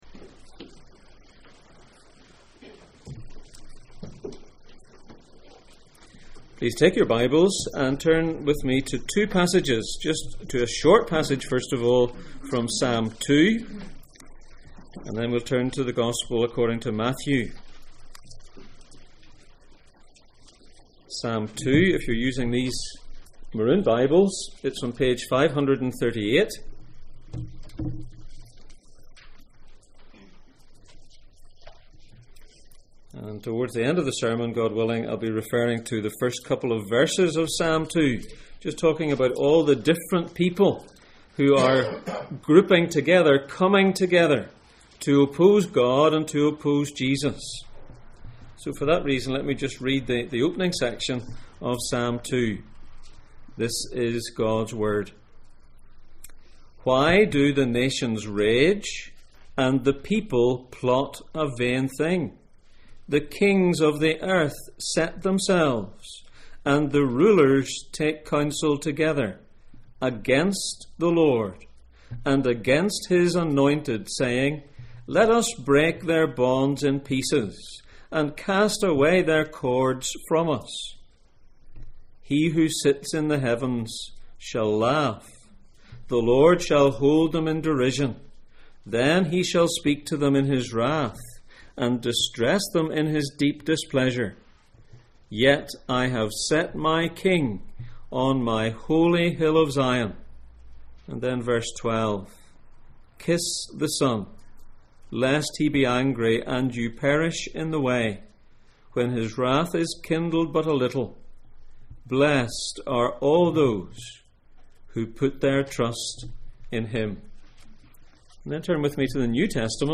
Matthew 28:1-20 Service Type: Sunday Morning %todo_render% « Why have elders?